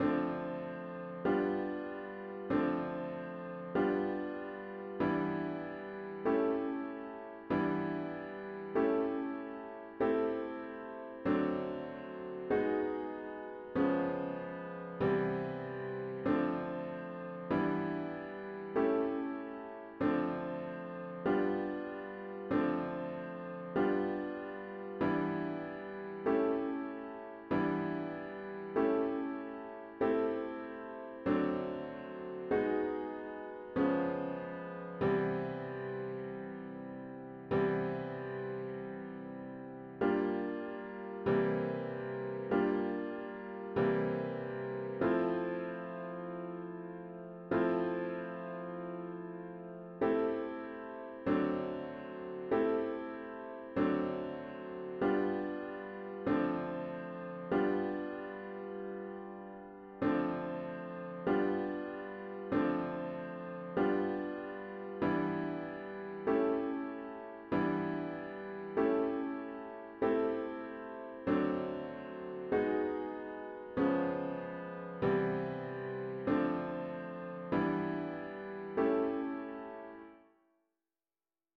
A chords only version of the score is found here.